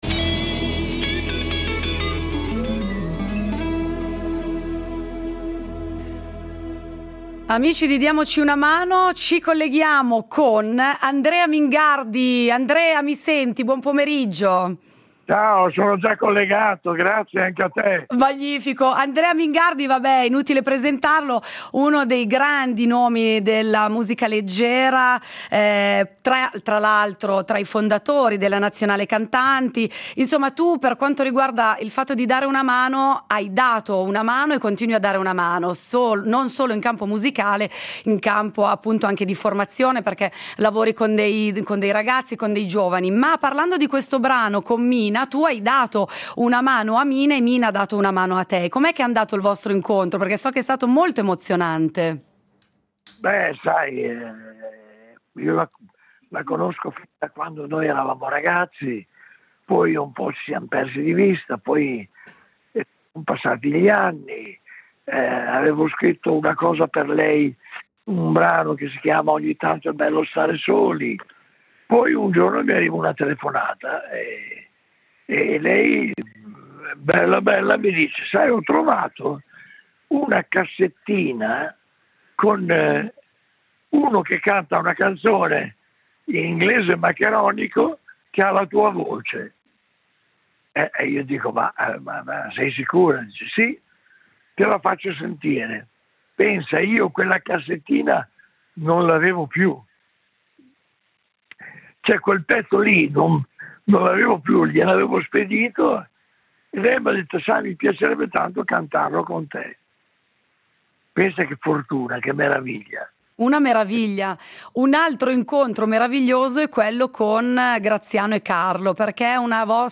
Incontro con Andrea Mingardi, cantautore e scrittore italiano